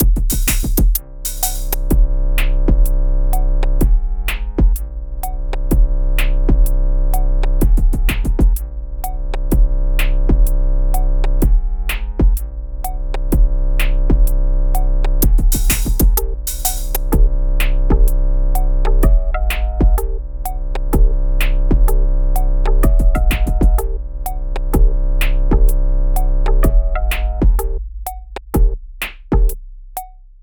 Bucle de Electroclash
Música electrónica
melodía
repetitivo
rítmico
sintetizador